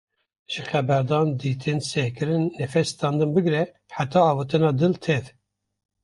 Pronounced as (IPA) /dɪl/